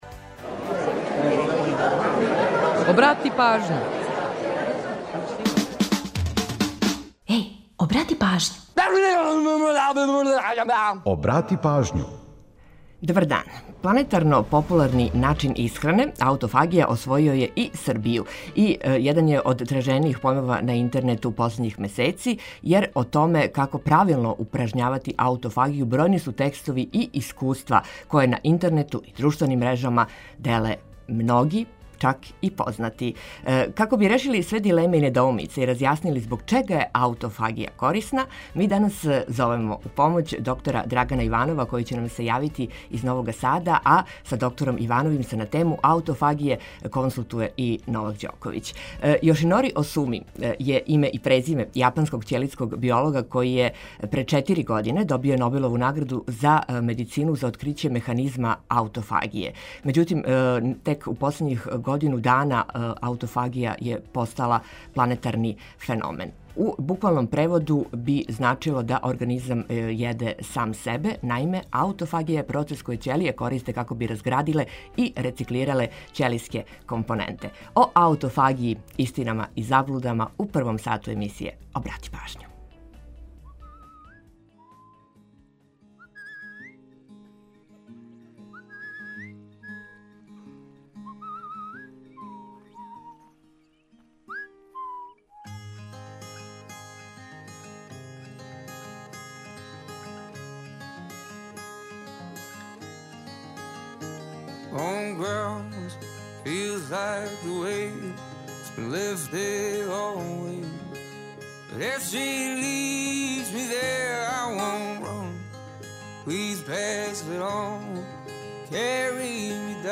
Ту је и пола сата резервисано само за музику из Србије и региона, а упућујемо вас и на нумере које су актуелне. Чућете и каква се то посебна прича крије иза једне песме, а за организовање дана, ту су сервисни подаци и наш репортер.